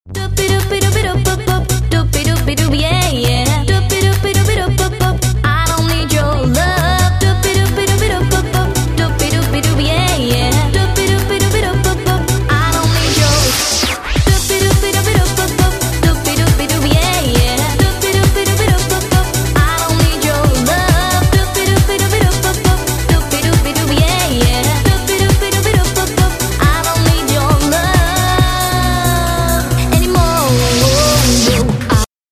• Качество: 192, Stereo
женский вокал
веселые
заводные
Eurodance
bubblegum pop
Заводная попса из 90-х